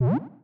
Add sound effects!
get-tool.ogg